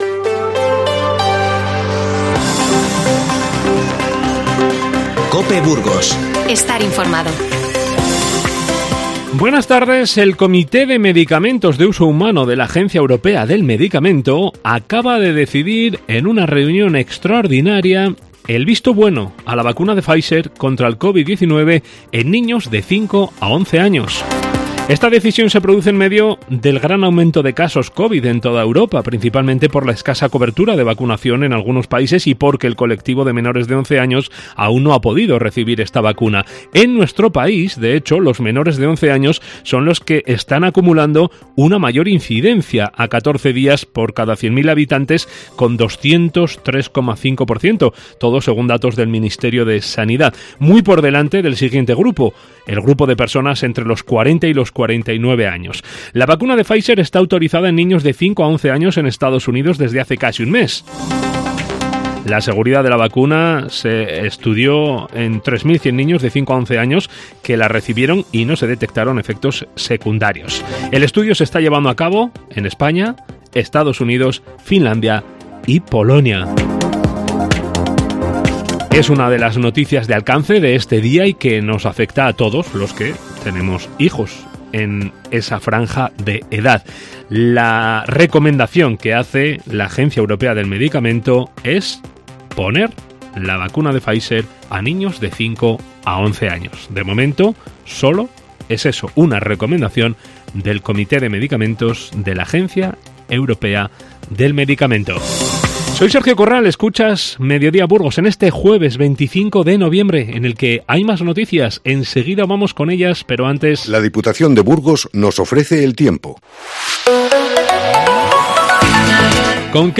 INFORMATIVO Mediodía 25-11-21